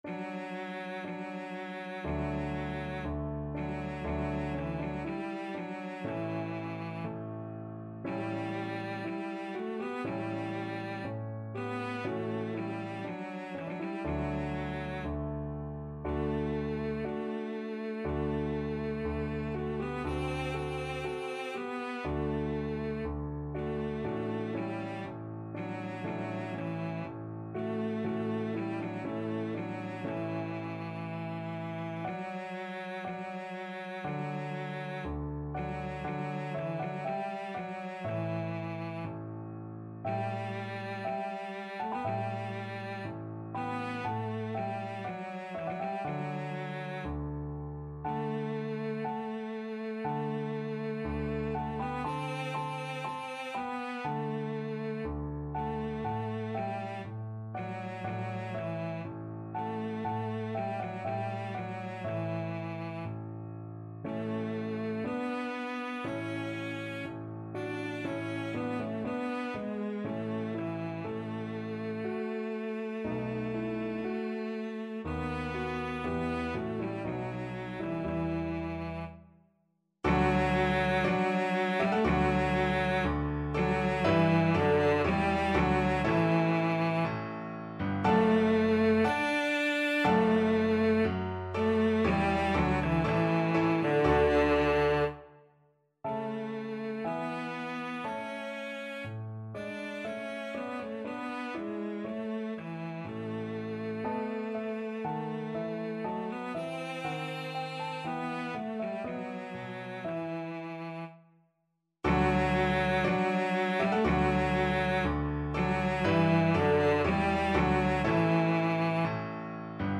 Classical Handel, George Frideric Funeral March from Saul Cello version
Cello
D major (Sounding Pitch) (View more D major Music for Cello )
4/4 (View more 4/4 Music)
Slow =c.60
Classical (View more Classical Cello Music)